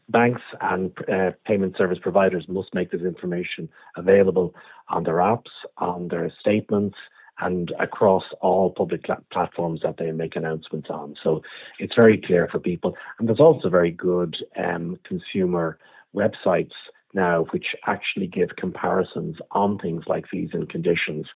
BPFI CEO Brian Hayes says the information is there, and people should check: